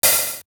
Index of dough-samples/ tidal-drum-machines/ machines/ LinnLM1/ linnlm1-oh/
LM-1_HH_2_TL.wav